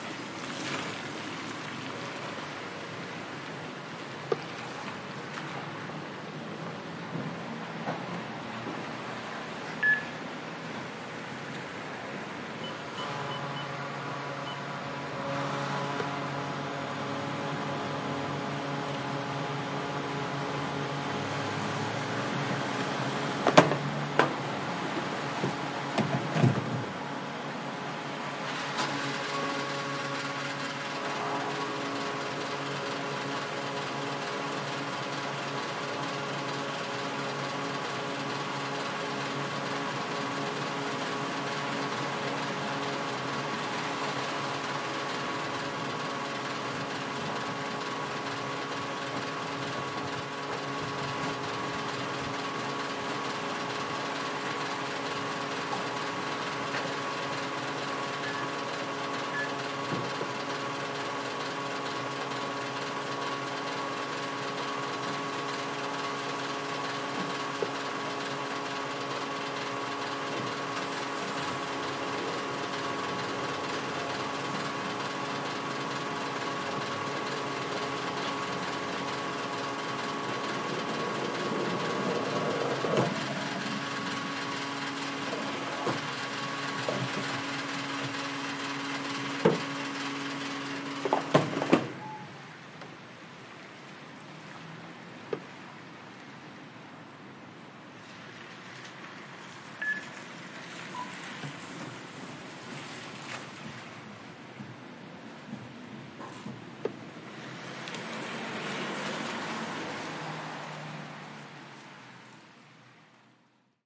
轮胎 汽车
描述：轮胎/轮胎被注入压缩空气的声音。话筒放在轮胎旁边的地面上。不幸的是，背景中有相当多的其他汽车噪音。我必须在某个深夜回去好好做。录音链。松下WM61A（麦克风） Edirol R09HR（数字录音机）。
标签： 气动 服务站 加油站 汽油站 压缩机 填充 嘶嘶 轮胎 空气 轮胎 汽车护理 压缩 压缩空气
声道立体声